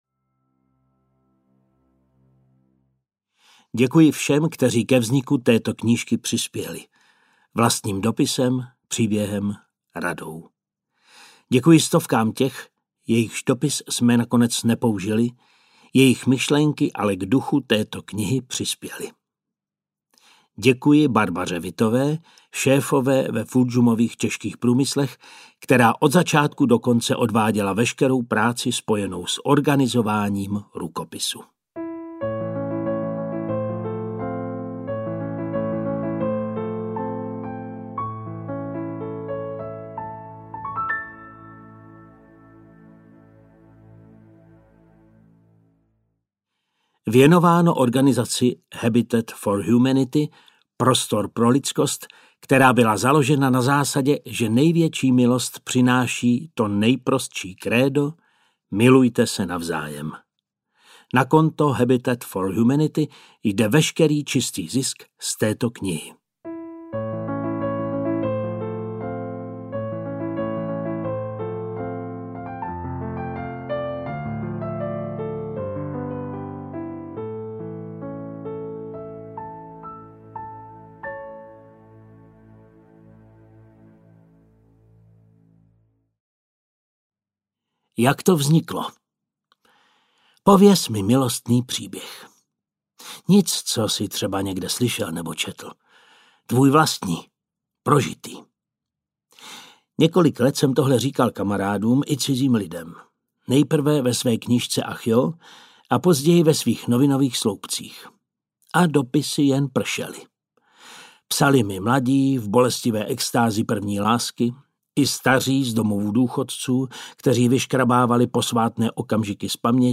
Opravdová láska audiokniha
Ukázka z knihy